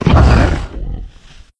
c_anusibath_hit1.wav